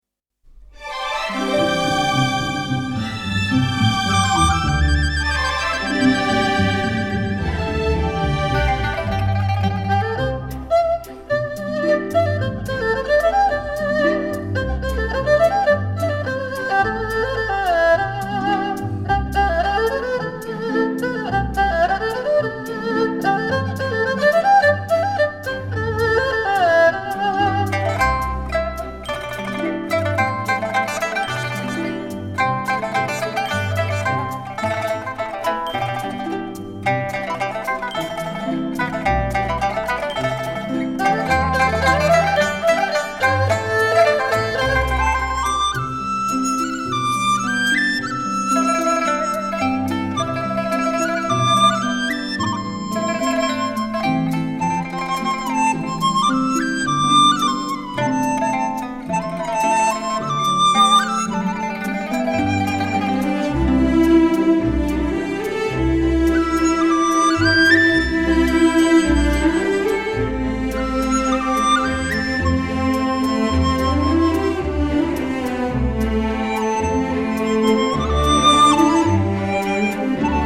480 平米錄音棚以全類比方式精心錄製！